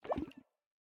sounds / mob / axolotl / idle1.ogg